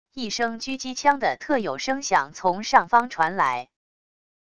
一声狙击枪的特有声响从上方传来wav音频